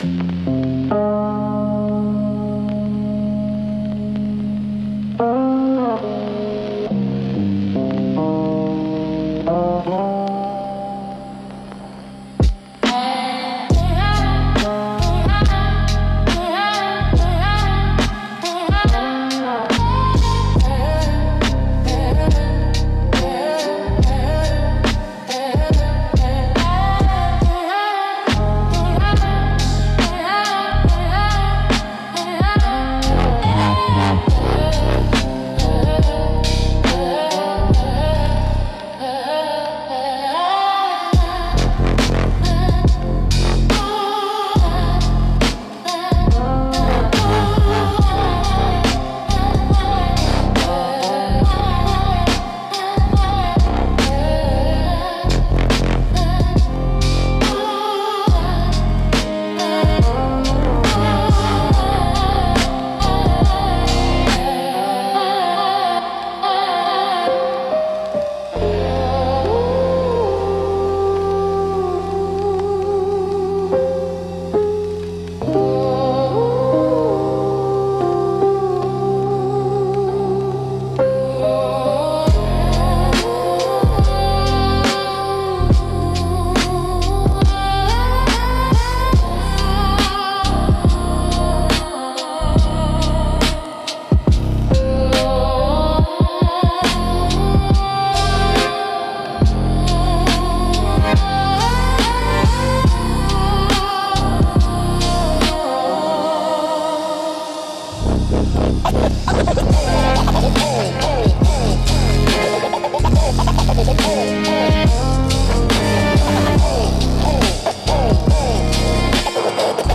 Instrumental - Dim Lit Drifter